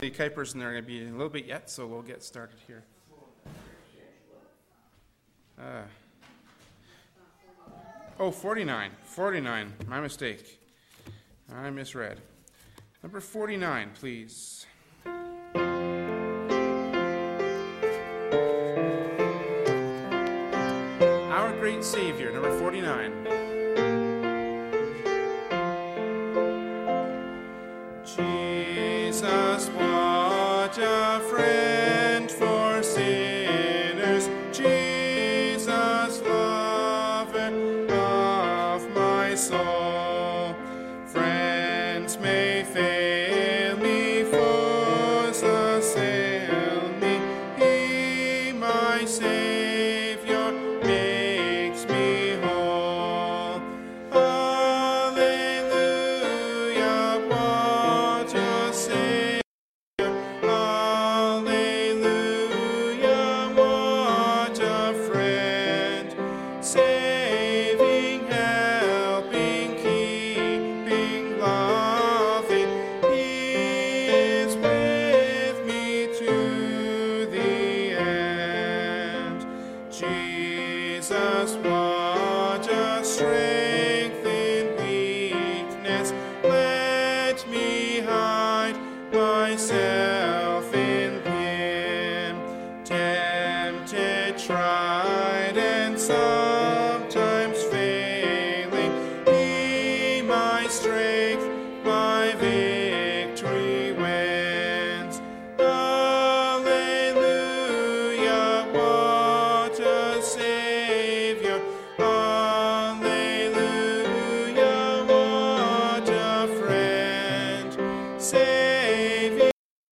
“1st Peter 1:4-5” from Wednesday Evening Service by Berean Baptist Church.